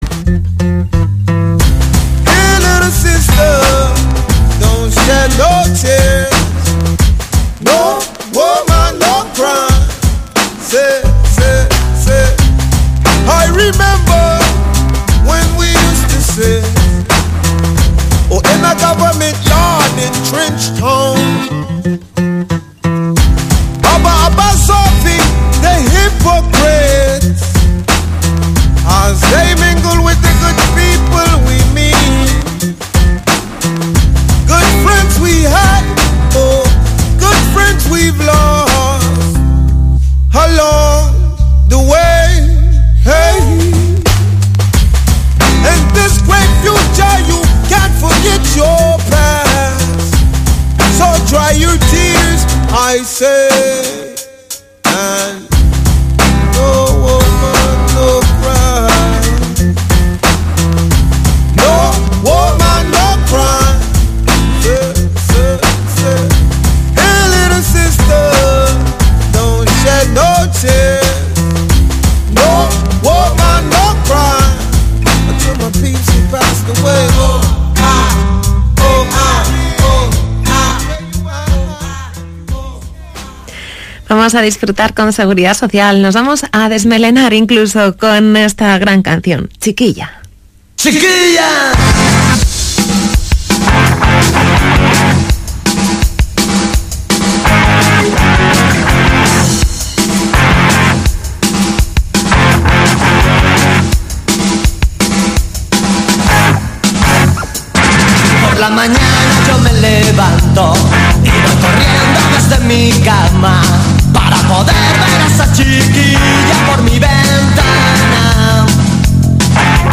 Kirolbet Baskonia-Candelas Breogan jornada 11 ACB retransmisión completa Radio Vitoria